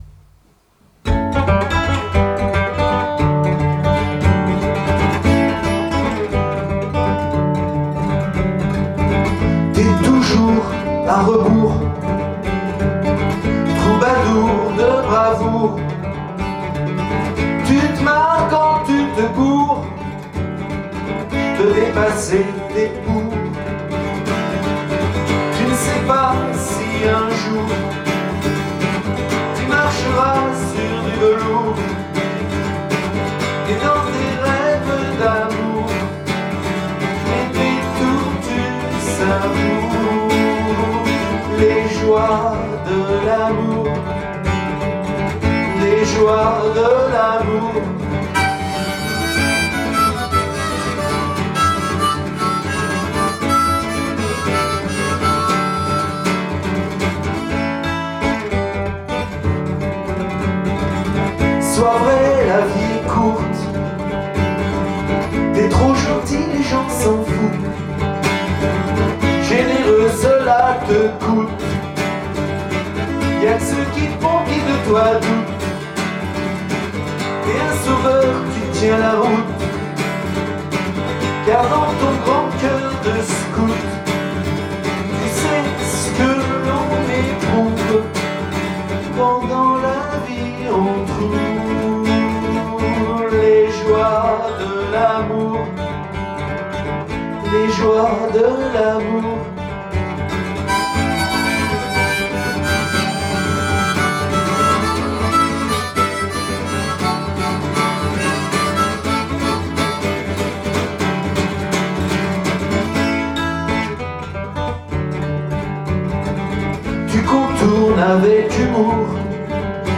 un rock jive qui donne des ailes.